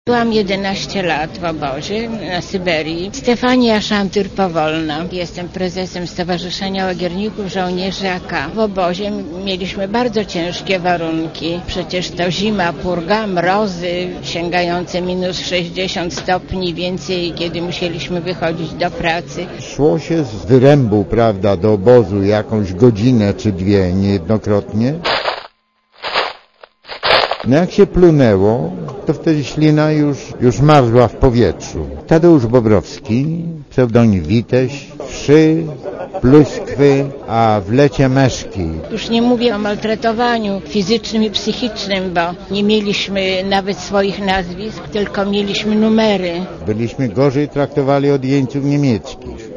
Mówią byli więźniowie obozów Gułag to nazwa obozów pracy rozsianych na obszarze dawnego Związku Radzieckiego, to skrót nazwy tłumaczonej jako Główny Zarząd Obozów Pracy.